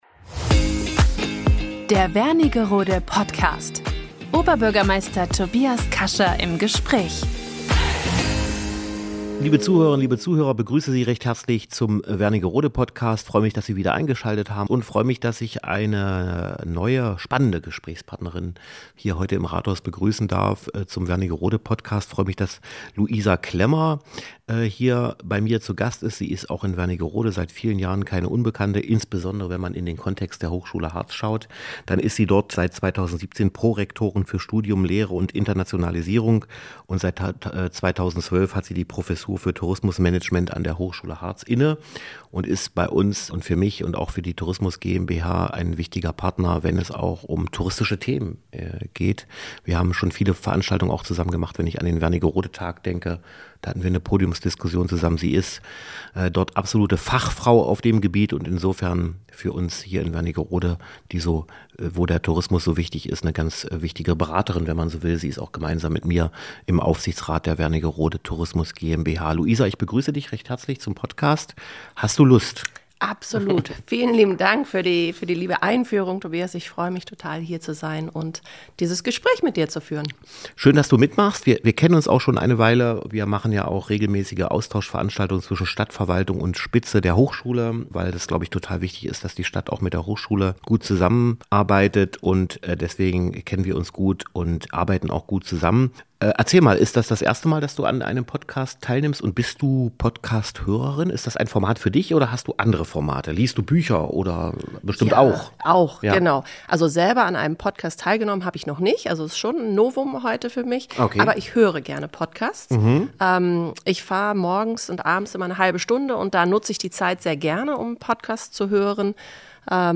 Wernigerode Podcast #45 - Oberbürgermeister Tobias Kascha im Gespräch